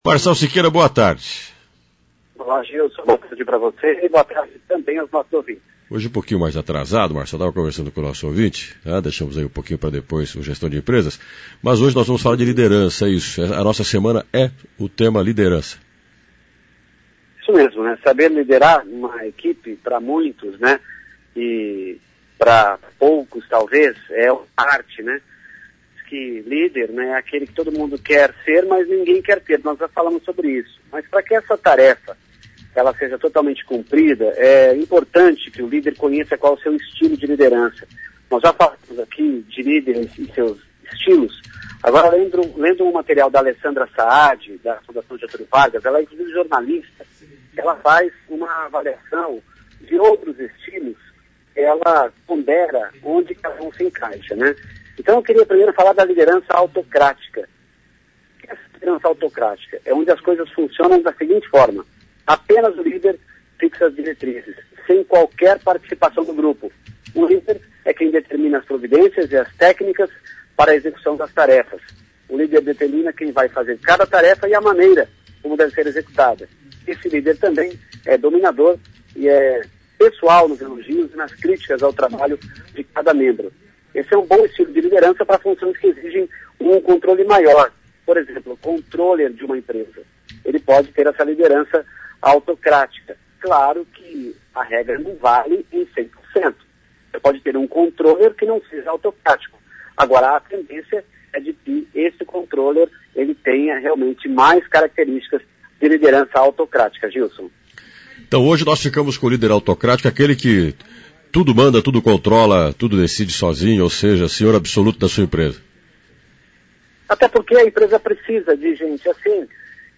Gestão de Empresas - ao vivo 15.09.mp3